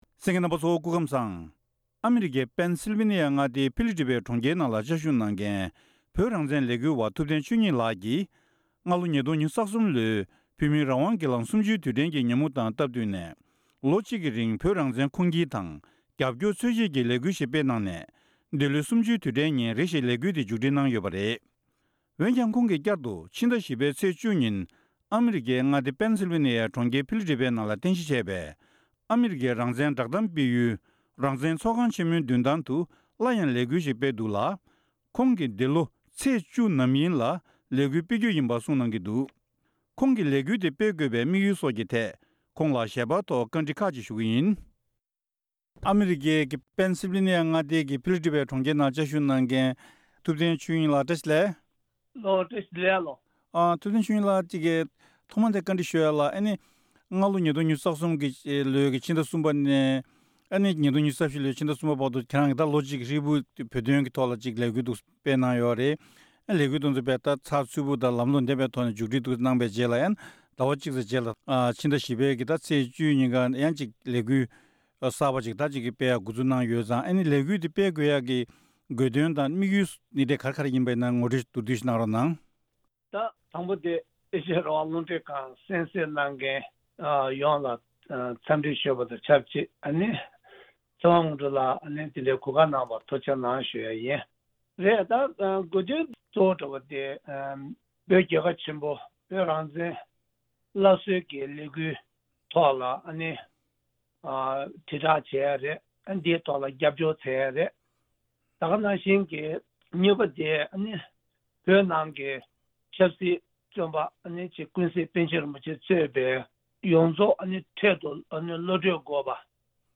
ཞལ་པར་ཐོག་བཀའ་འདྲི་ཞུས་པ་ཞིག་གསན་རོགས་གནང་།